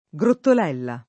Grottolella [grottol%lla o
grottol$lla] top. (Camp.) — con -e- chiusa la pn. loc.; con -e- aperta la pn. naturale di chi non è del luogo, e coerente del resto con la normale pn. del suffisso (diminutivo, come qui) -ello